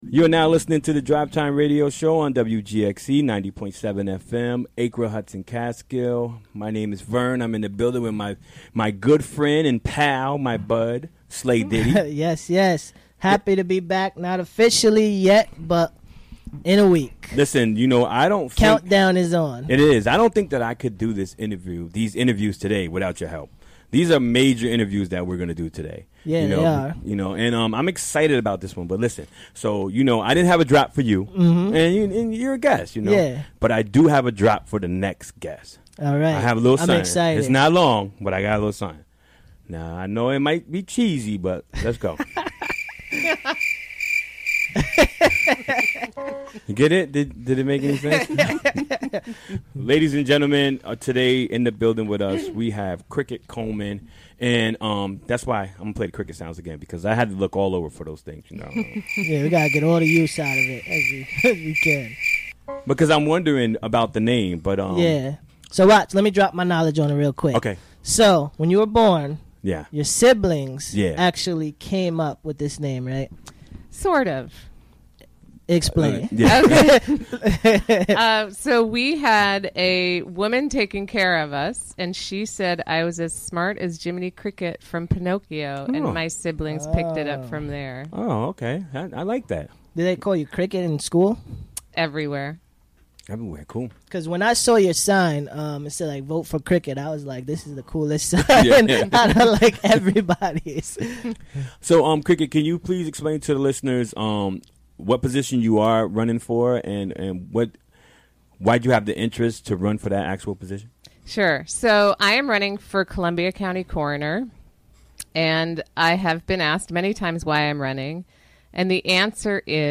Recorded during the WGXC Afternoon Show Wednesday, October 25, 2017.